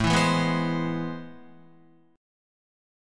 AmM9 chord